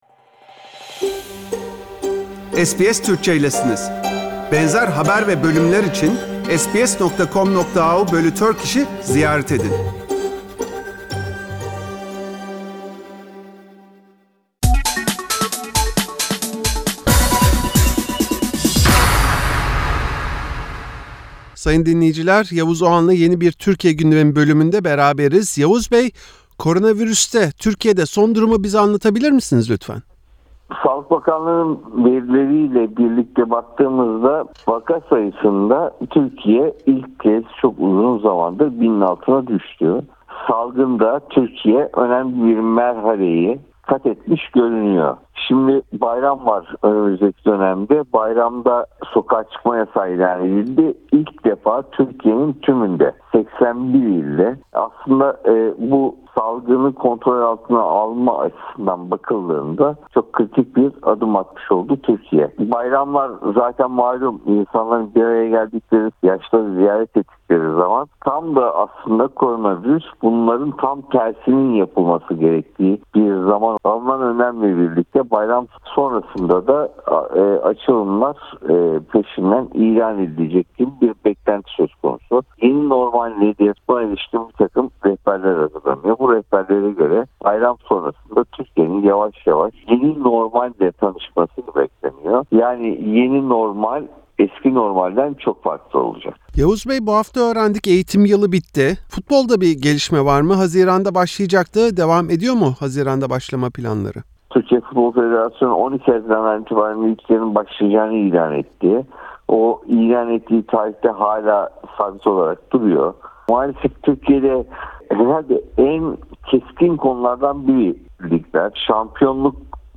SBS Türkçe’ye Türkiye’nin COVID-19’la mücadelede kat ettiği yolu anlatan gazeteci Yavuz Oğhan, hükümetin Pazar günü başlayacak Ramazan Bayramı için tüm ülke çapında sokağa çıkma yasağı kararı aldığını ancak bayram sonrasında yeni normale geçiş için düğmeye basacağını söyledi.